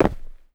Terrarum/assets/mods/basegame/audio/effects/steps/ROCK.1.wav at 8a1ff32fa7cf78fa41ef29e28a1e4e70a76294ea
ROCK.1.wav